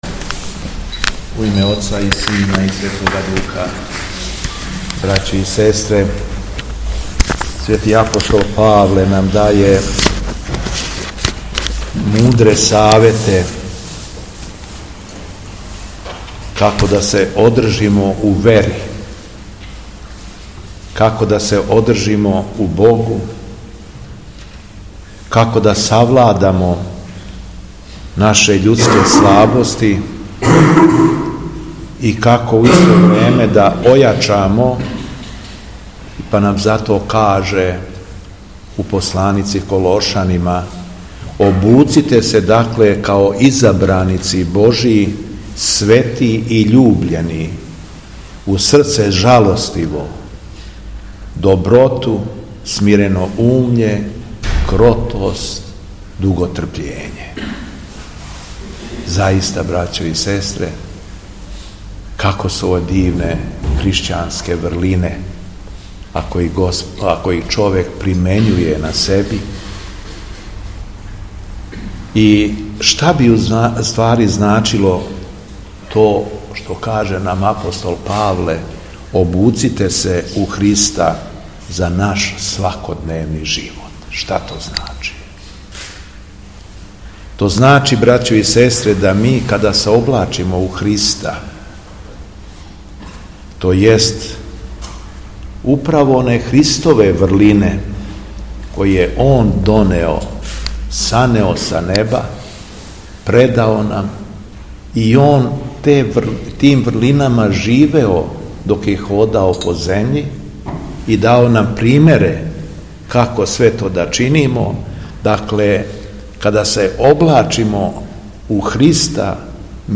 Уторак, 26. децембра 2023. године, Његово Преосвештенство Епископ шумадијски Господин Јован служио је Свету Архијерејску литургију у храму Светог великомученика Пантелејмона у Станову.
Беседа Његовог Преосвештенства Епископа шумадијског г. Јована
Након прочитаног јеванђелског зачал, Епископ се беседом обратио вернима, рекавши: